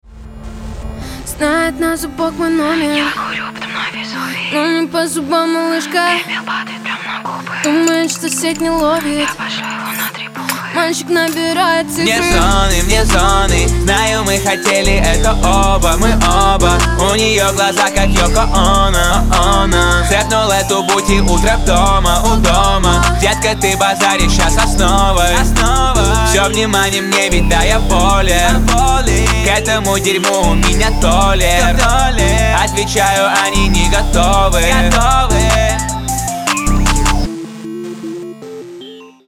• Качество: 320, Stereo
дуэт
фонк